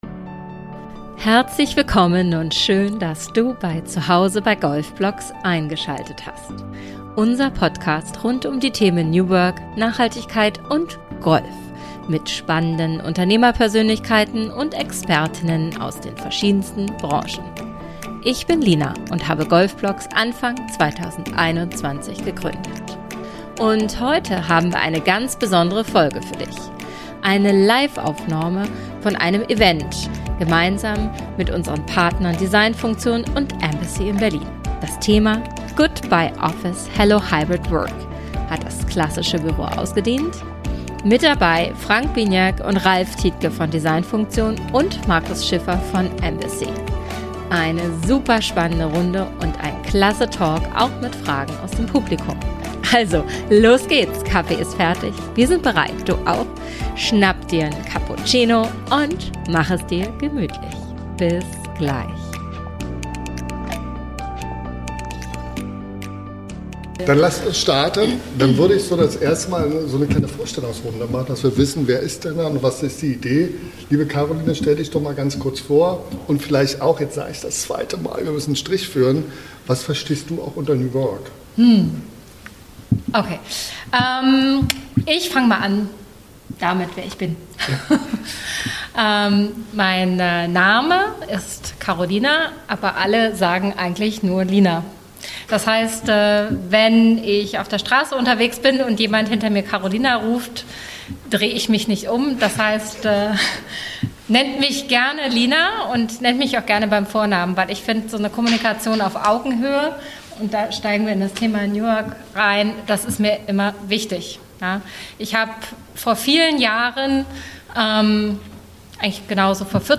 Eine Live Aufnahme vom Event unseres Partners designfunktion in Berlin.